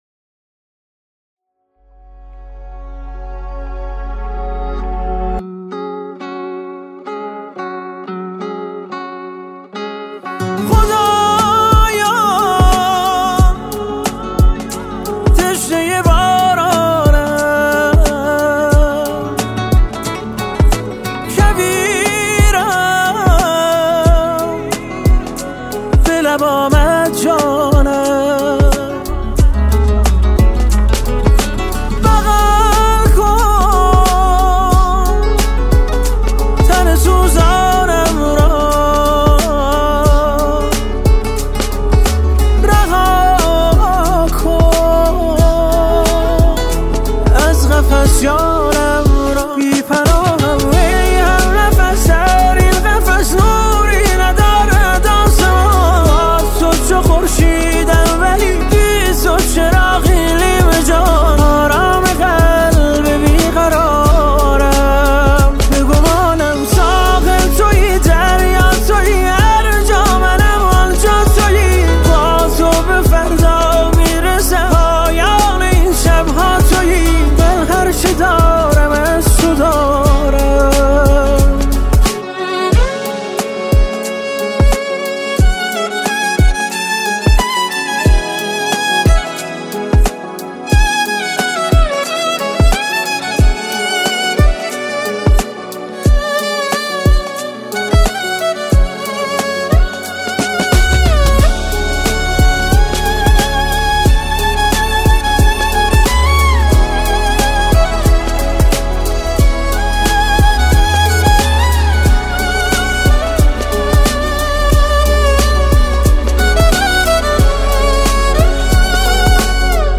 جدیدترین اهنگ زیبا و احساسی